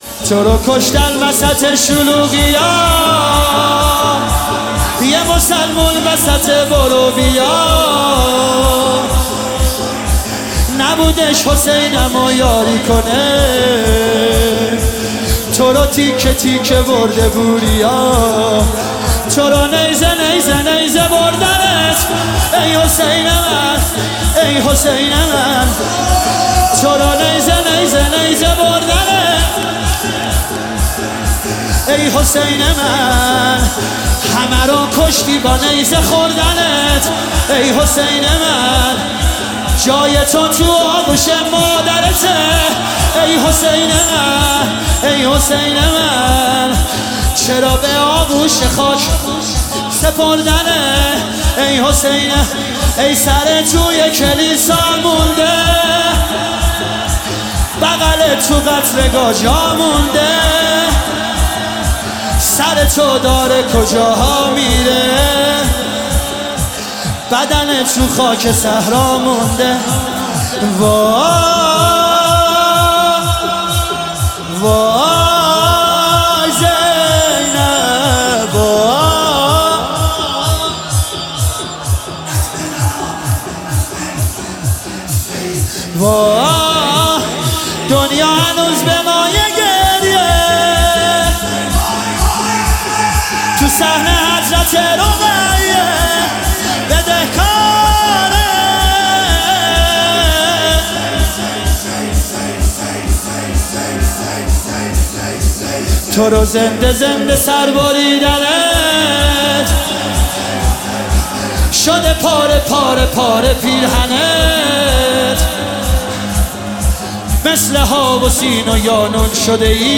مداحی شور لطمه زنی